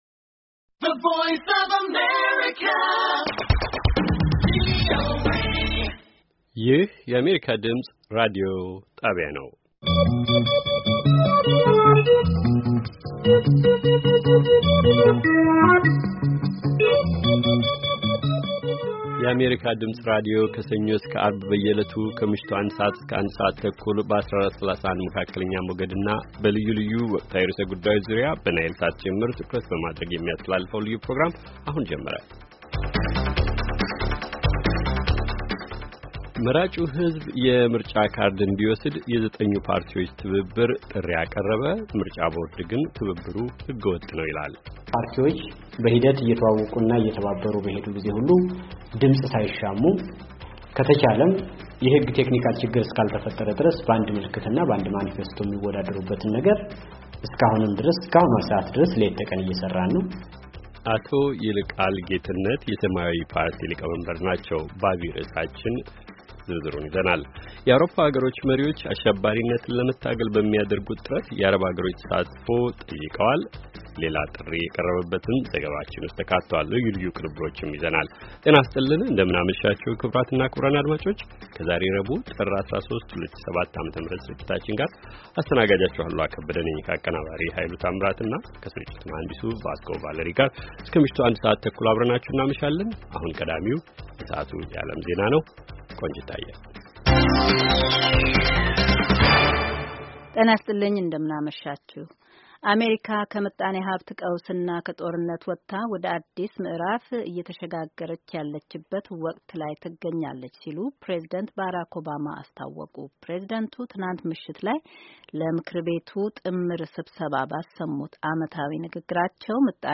ከምሽቱ ኣንድ ሰዓት የአማርኛ ዜና